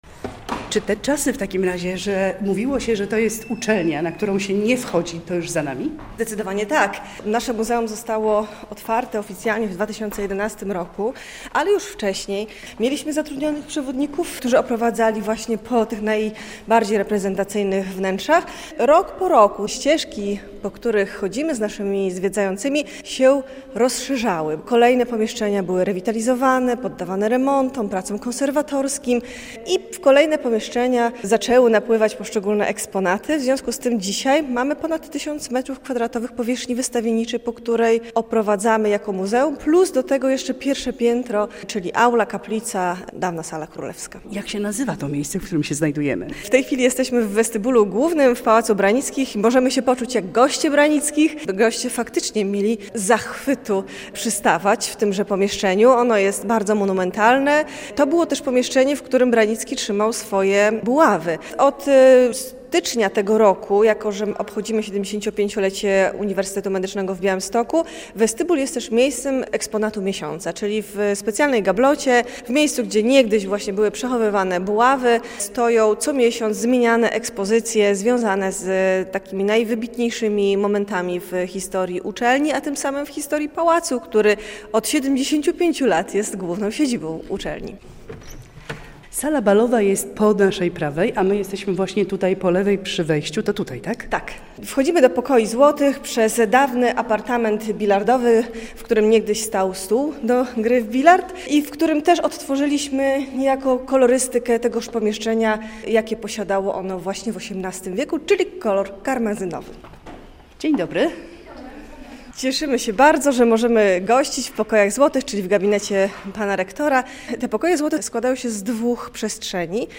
Złote pokoje Pałacu Branickich do zwiedzania - relacja